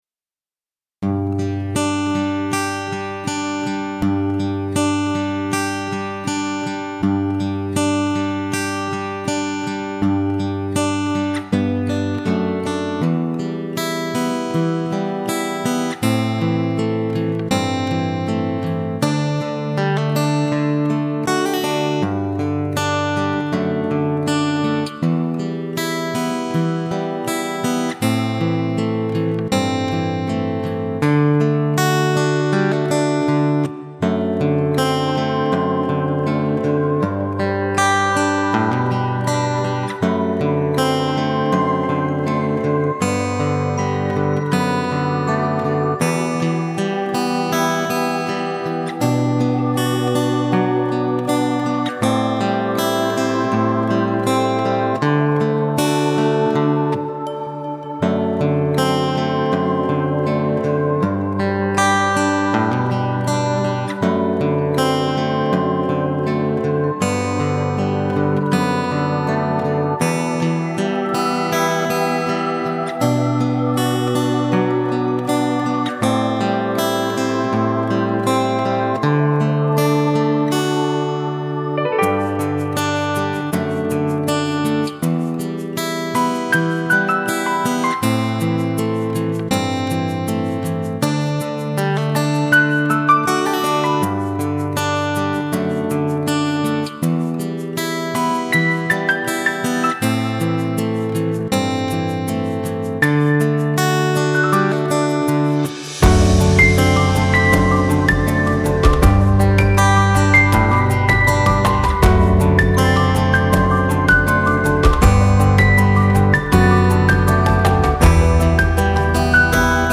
Фонограмма